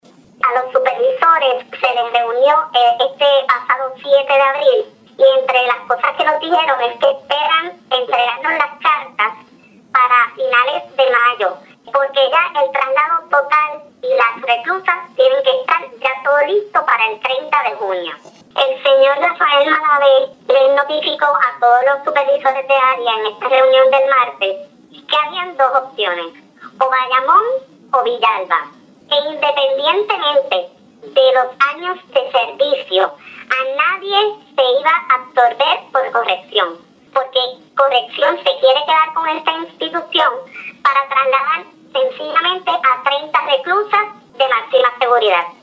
Escuche parte de la entrevista con la empleada (la voz ha sido distorsionada para proteger su identidad)